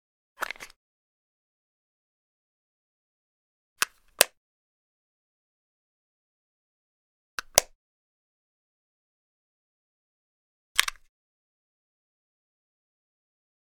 household
Cosmetic Hard Cover Make Up Case Flip Open